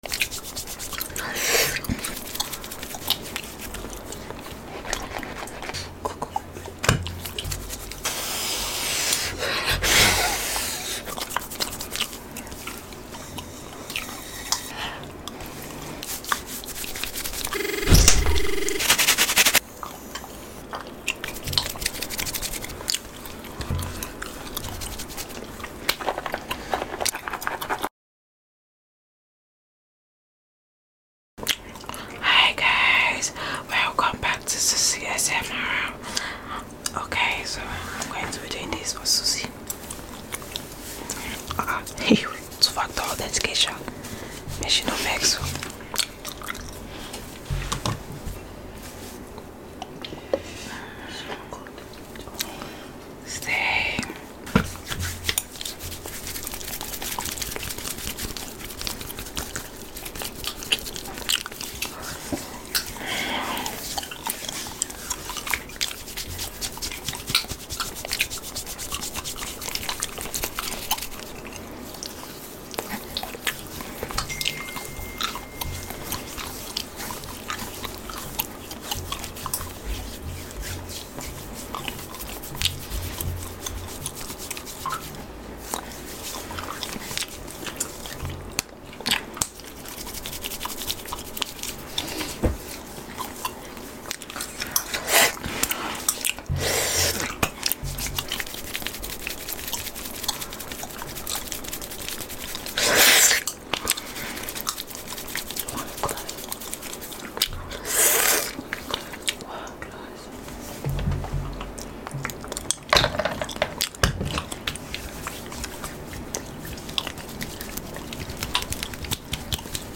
ASMR💫AYURVEDIC MASSAGE AND SPIT PAINTING sound effects free download